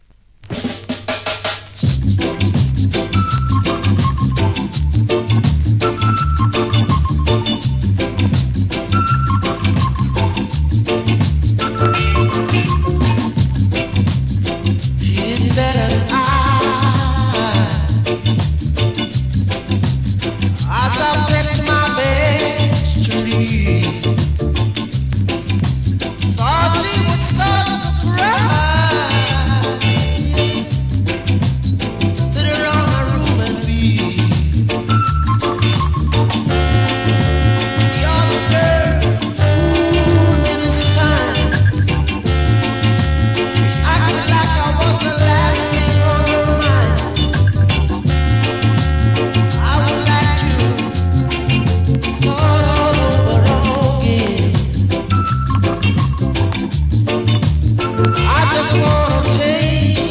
¥ 1,540 税込 関連カテゴリ REGGAE 店舗 ただいま品切れ中です お気に入りに追加